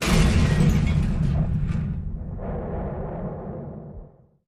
Single explosion with structure collapse at end.